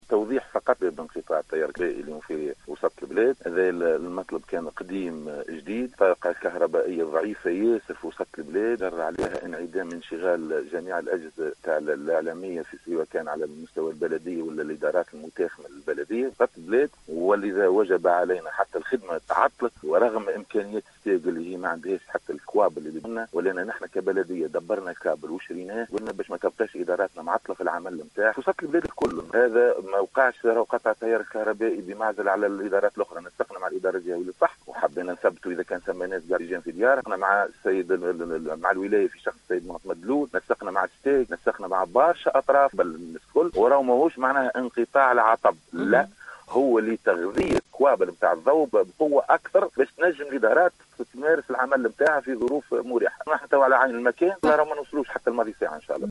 على خلفية انقطاع التيار الكهربائي اليوم الأحد 11 جويلية 2021 بعدد من أحياء القصرين المدينة أوضح رئيس بلديّة القصرين محسن المدايني في اتصال بإذاعة السيليوم أ ف أم اليوم أنّ  أسباب انقطاع التيّار تعود إلى أنّ الطاقة الكهربائيّة ضعيفة جدّا في وسط المدينة ، و هو ما انجرّ عنه عدم اشتغال جميع أجهزة الإعلاميّة على مستوى البلديّة و الإدارات المتاخمة لها ، و بالتالي تعطّل الخدمات.